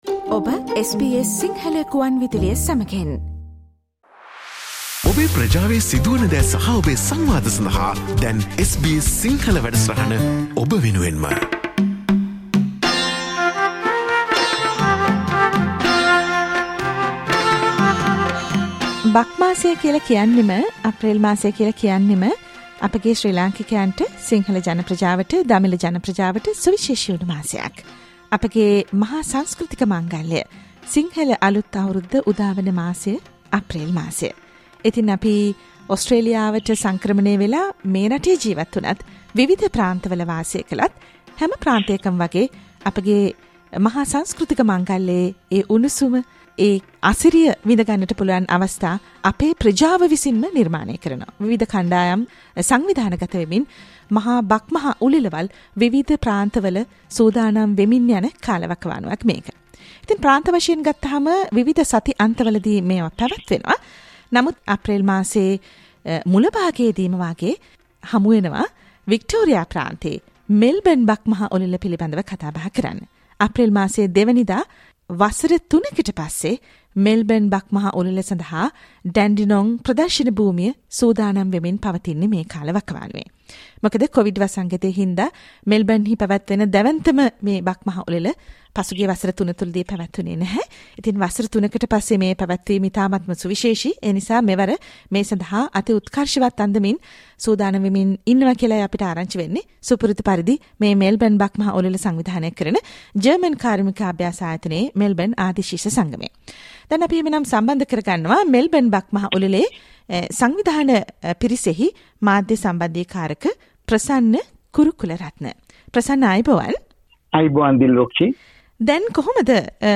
SBS Sinhala Radio interview on Melbourne's biggest Sinhala New Year Festival 2023_ Melbourne Bak Maha Ulela